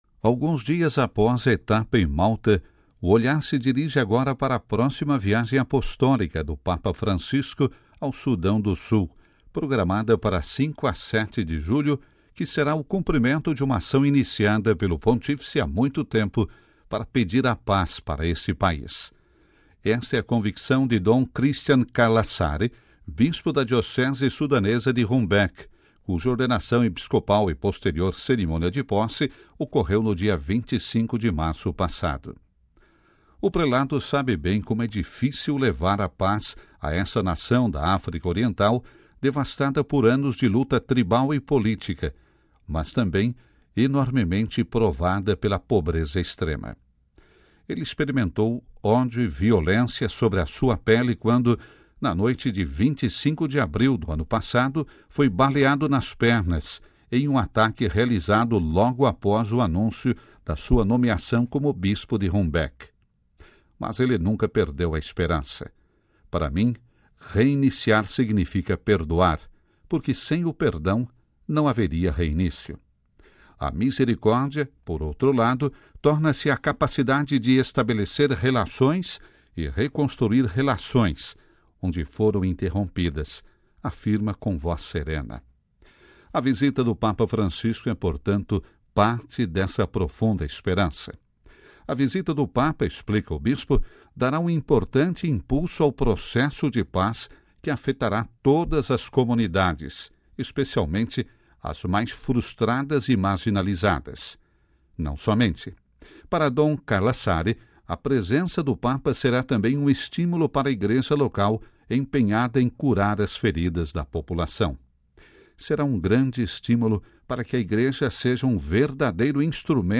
Dom Christian Carlassare, bispo da diocese sudanesa de Rumbek